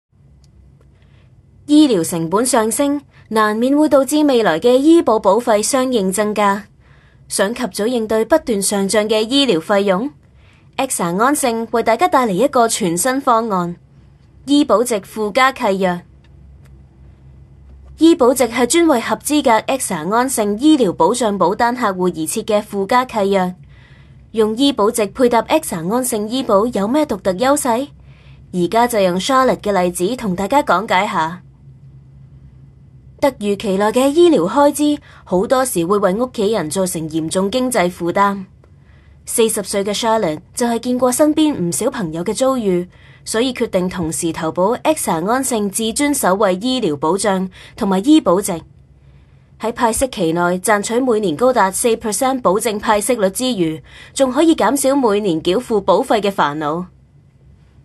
Voice Samples: Cantonese Voice Sample
female